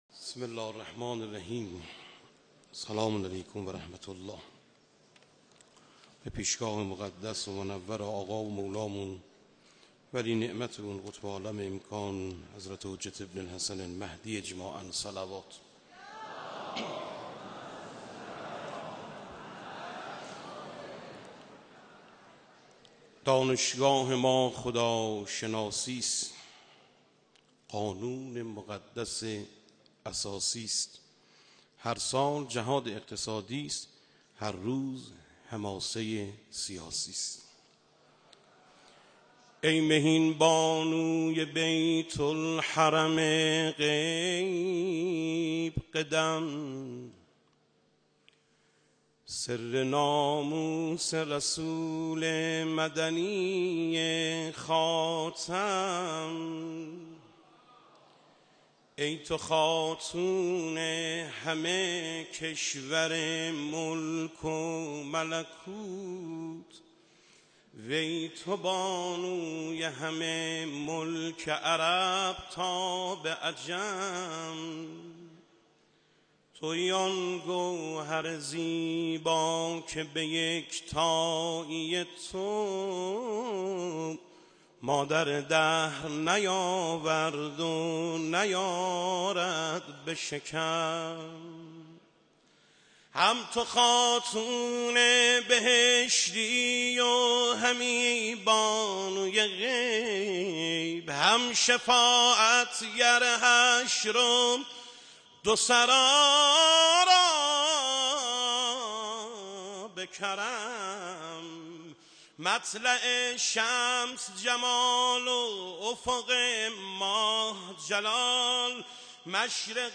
مدح خوانی | دانشگاه ما خداشناسی است
در سالروز ولادت حضرت فاطمه (س) با حضور رهبر انقلاب اسلامی | حسینیه امام خمینی(ره)